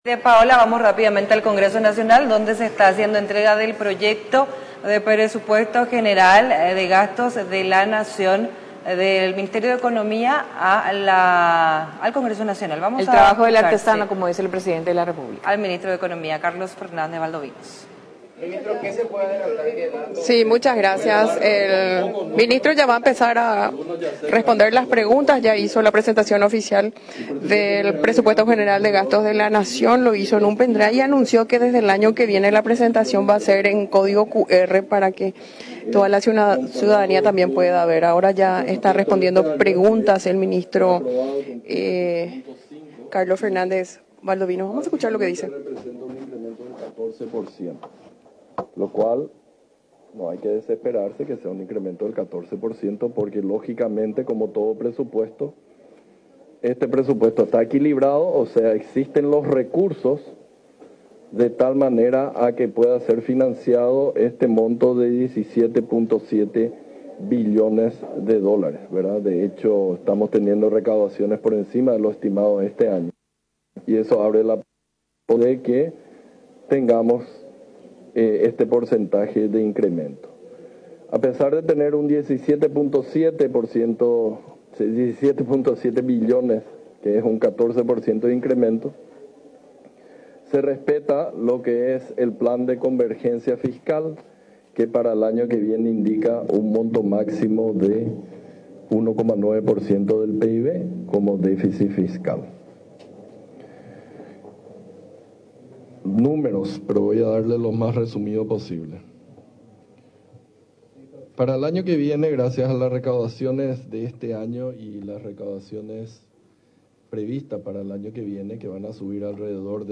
El secretario de Estado, acompañado de sus viceministros Oscar Lovera y Javier Charotti, destacó en rueda de prensa, que el monto total asciende a unos USD 17.557 millones, que representa un 14% más que el vigente.